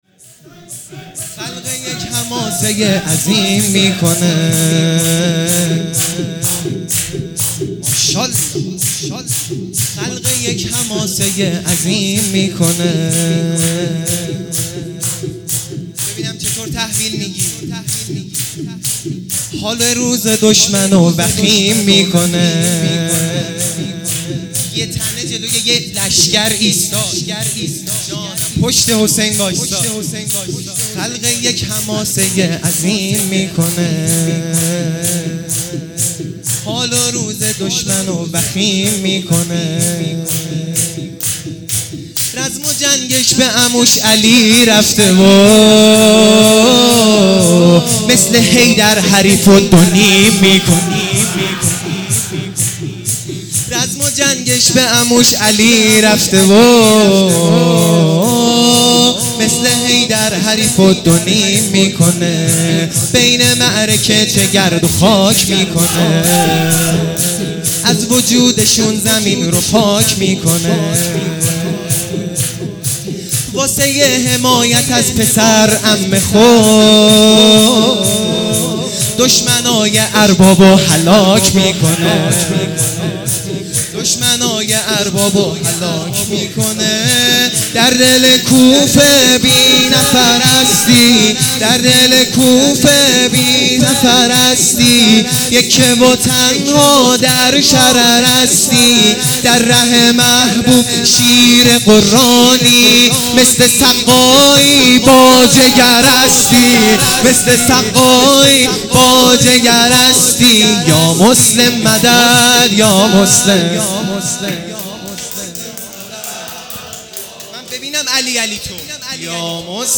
شور
شهادت امام باقر علیه السلام